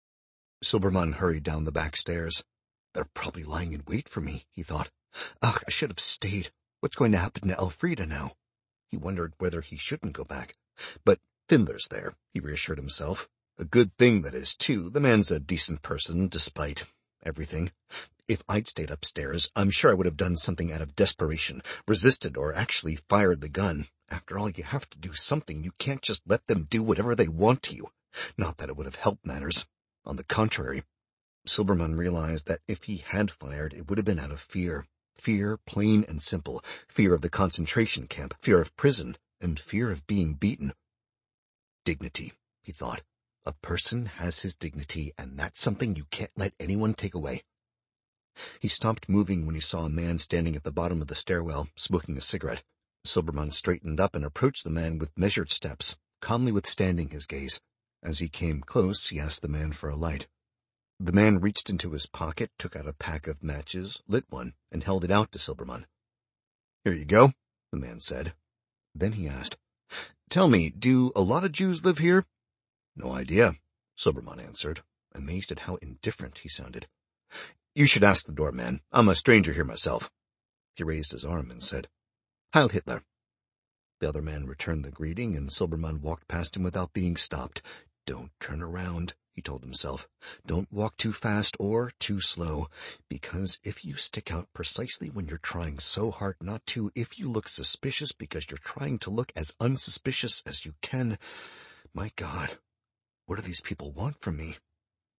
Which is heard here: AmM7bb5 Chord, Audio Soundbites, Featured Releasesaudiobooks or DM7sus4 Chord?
Featured Releasesaudiobooks